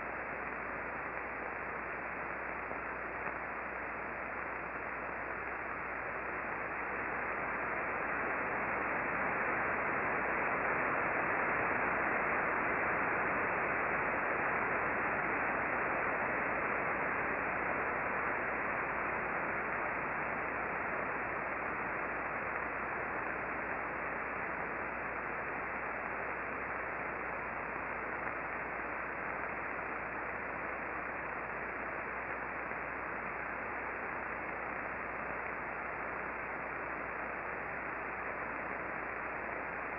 Solar Radio Observations for 21 April 2013
Click on the spectrogram or chart above for a monaural recording (31.972 MHz) of this burst